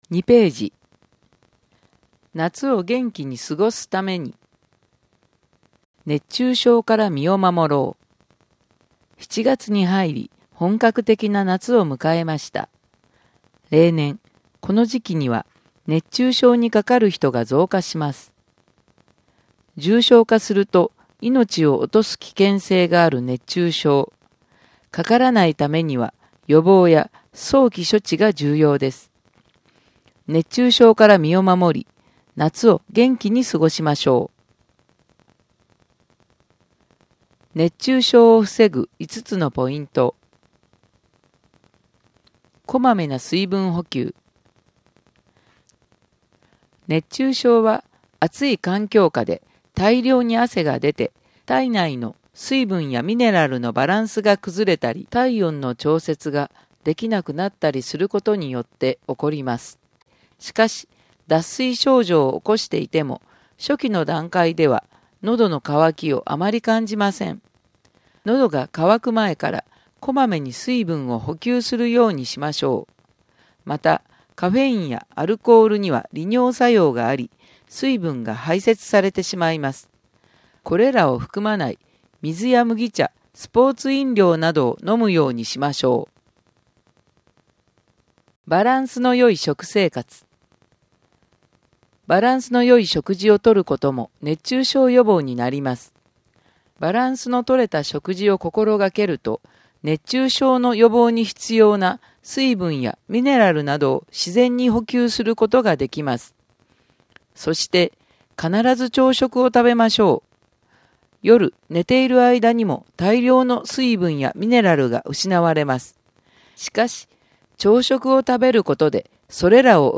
音訳広報たわらもと2〜3ページ (音声ファイル: 3.6MB)